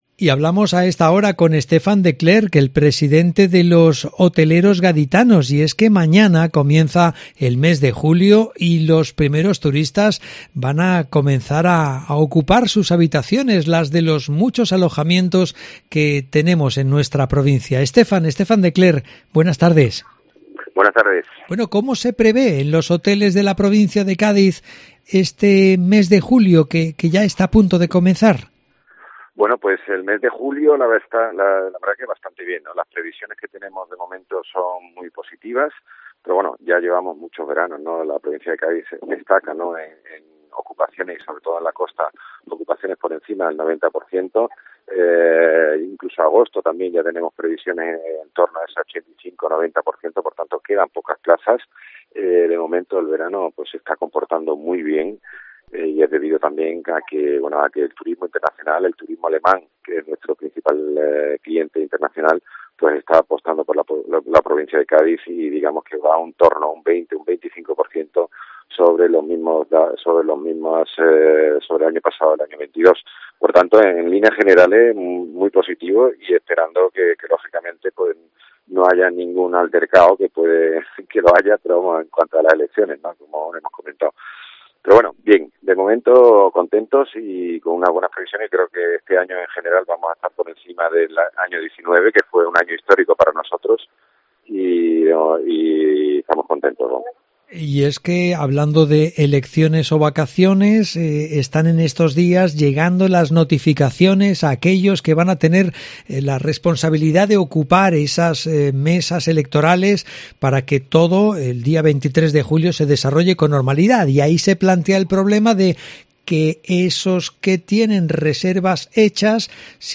habla en COPE de las buenas perspectivas